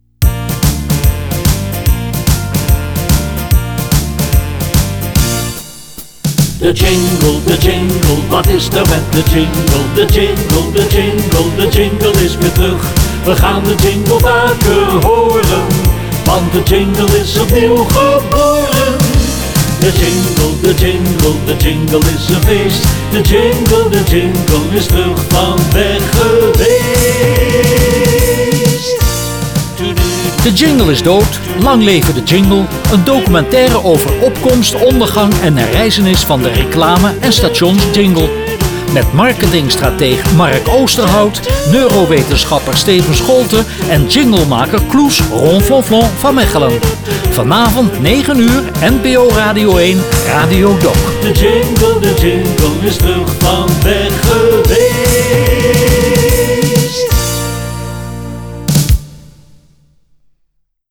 We hebben ook drie jingles gemaakt.
jingle 1